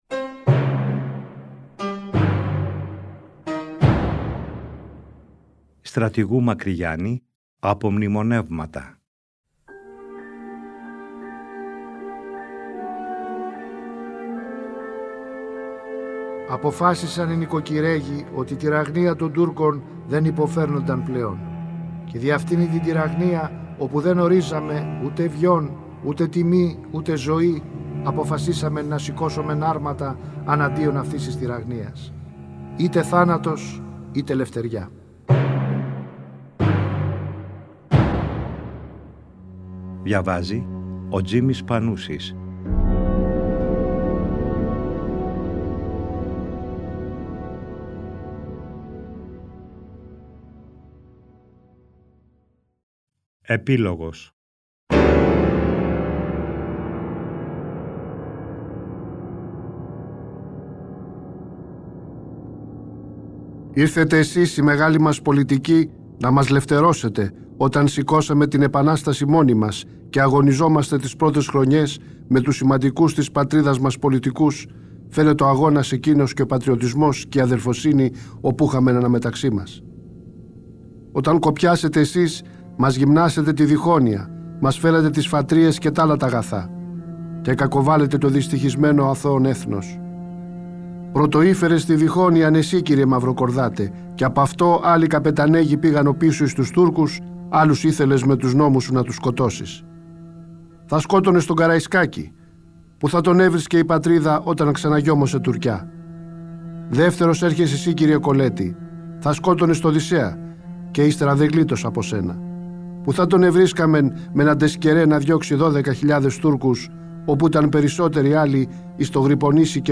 Τον Ιούνιο του 2012 το Τρίτο Πρόγραμμα παρουσίασε για πρώτη φορά μια σειρά 60 ημίωρων επεισοδίων, με τον Τζίμη Πανούση να διαβάζει τον γραπτό λόγο του Μακρυγιάννη, όπως ο ίδιος ο Στρατηγός τον αποτύπωσε στα “Απομνημονεύματα” του. Το ERT εcho σε συνεργασία με το Τρίτο Πρόγραμμα αποκατέστησαν ψηφιακά τα αρχεία.
Ανάγνωση: Τζίμης Πανούσης